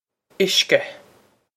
uisce ish-ka
This is an approximate phonetic pronunciation of the phrase.